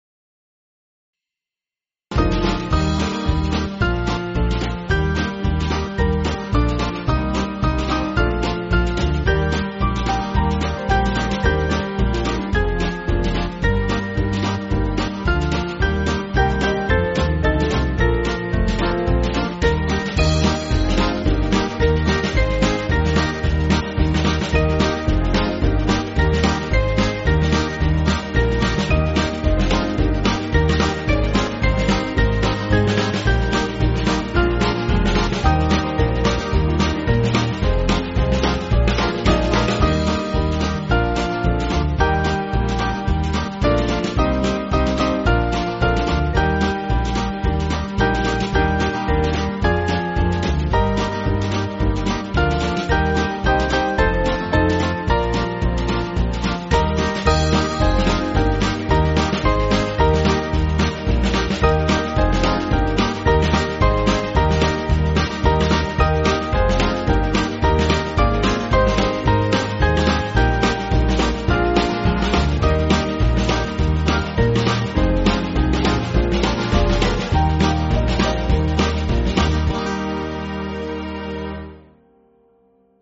Small Band
(CM)   2/Eb